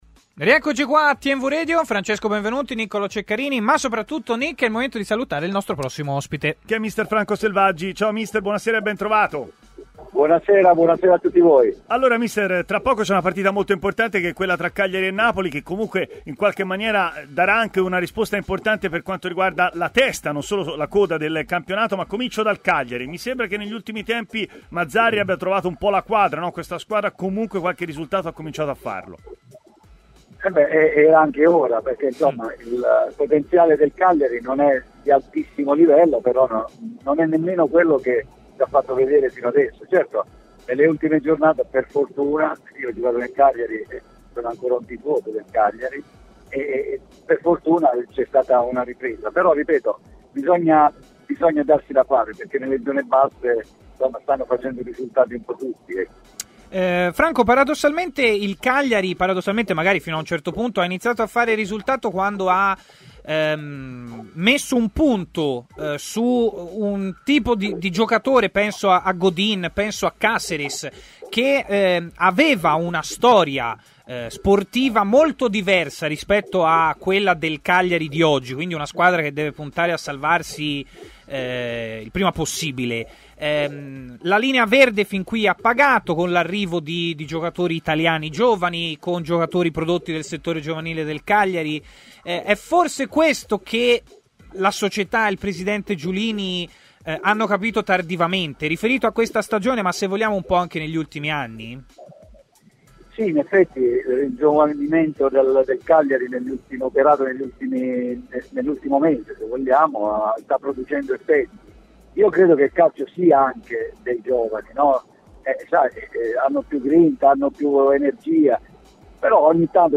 L’ex attaccante Franco Selvaggi, oggi allenatore, ha parlato a Stadio Aperto, trasmissione di TMW Radio